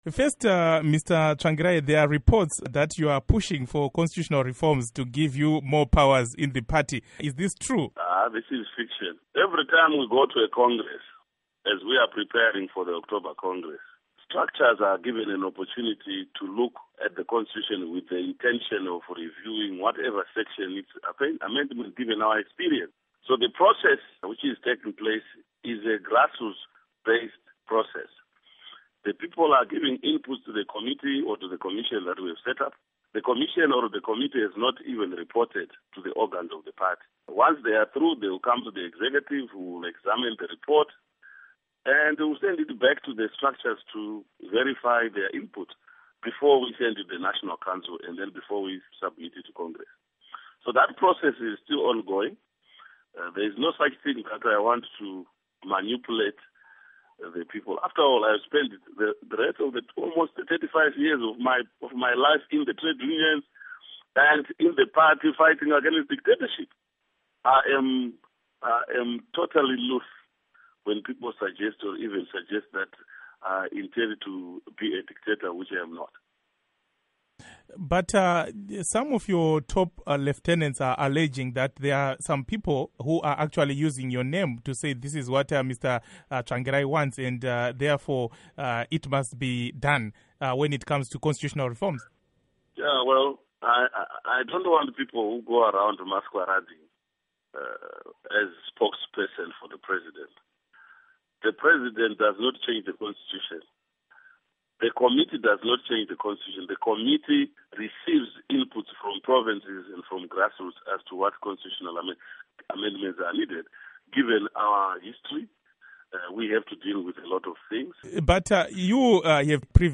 Interview With Morgan Tsvangirai